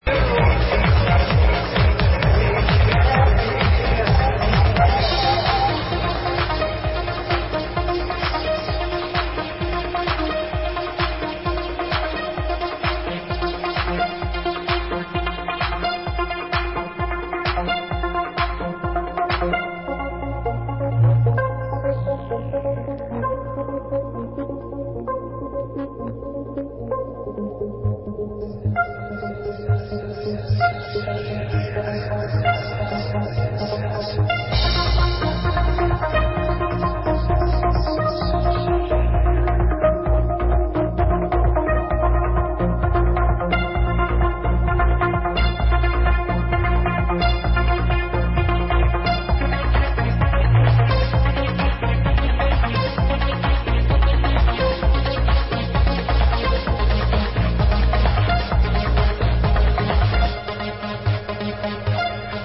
Live @ Loca, Diakofto 8-31-02